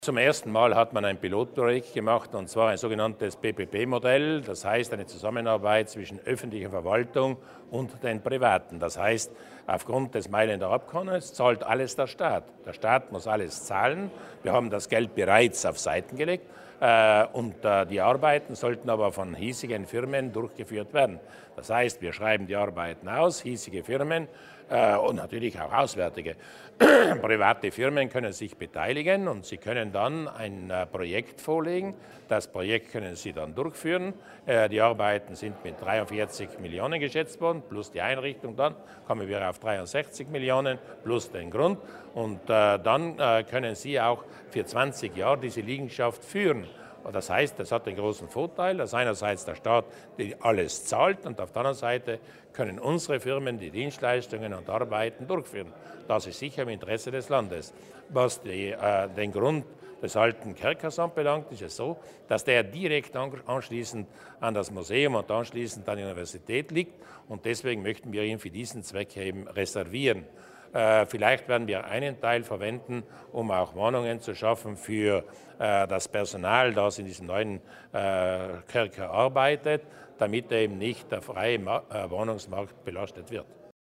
Landeshauptmann Durnwalder erläutert die Neuheit der Projektfinanzierung für das neue Gefängnis